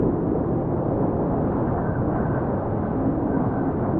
Owl Hoot
Owl Hoot is a free animals sound effect available for download in MP3 format.
345_owl_hoot.mp3